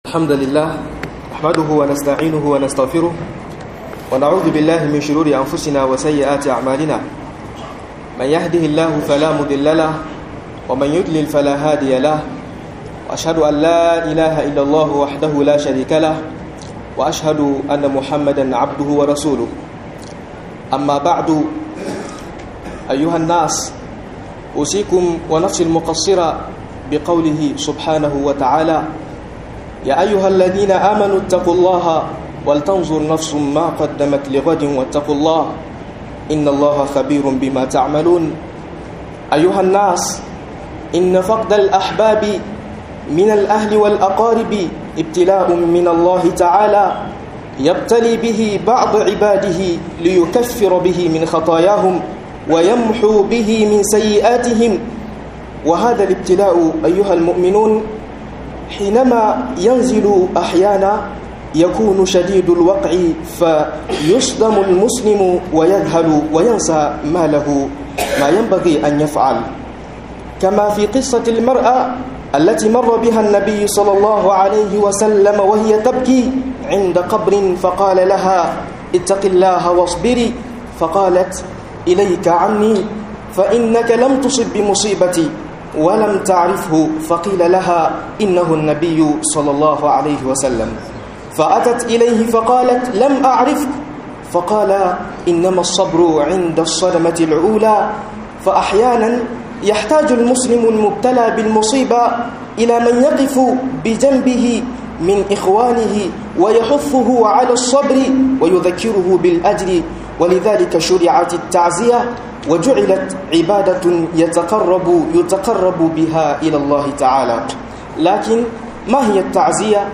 ladubba da fa'idodin ta'aziyya - MUHADARA